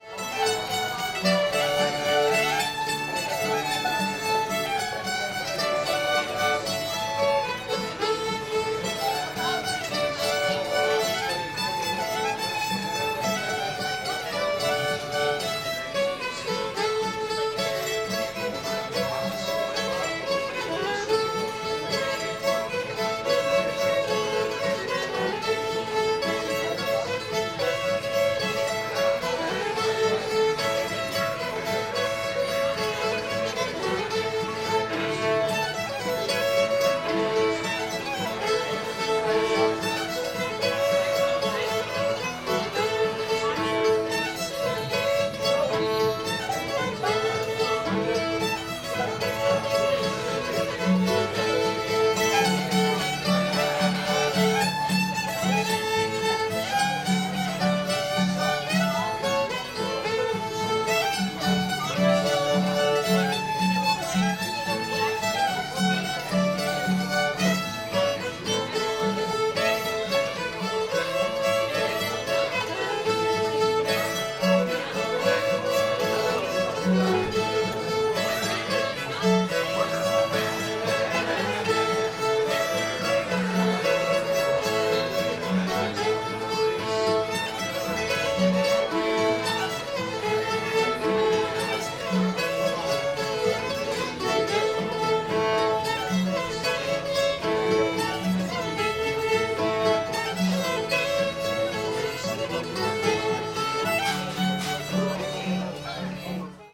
great big taters in the sandy land [A]